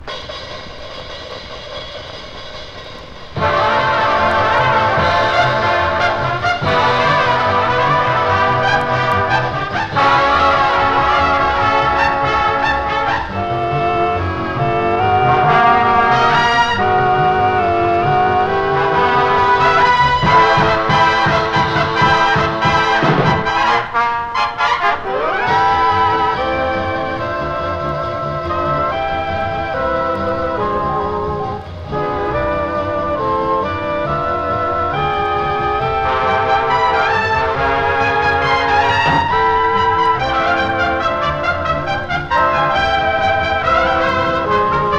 Jazz, Big Band　USA　12inchレコード　33rpm　Mono